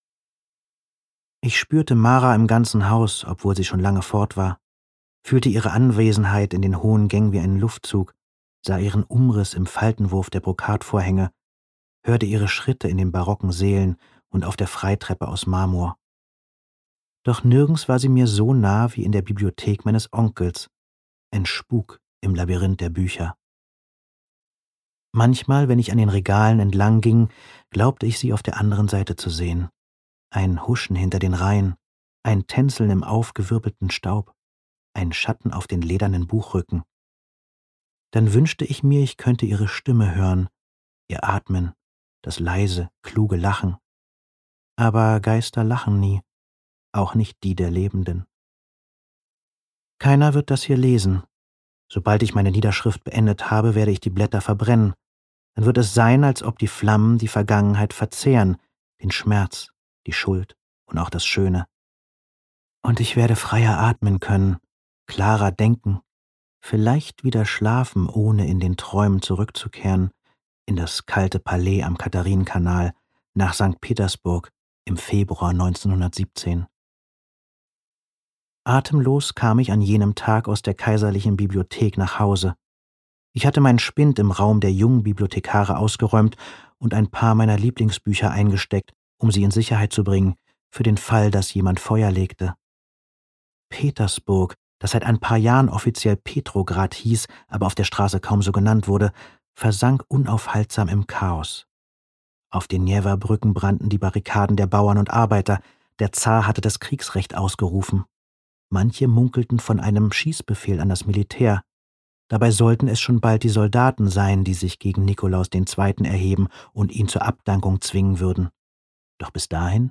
Die Bibliothek im Nebel Roman Kai Meyer (Autor) Fabian Busch , Luise Helm , Johann von Bülow (Sprecher) Audio Disc 2023 | 3.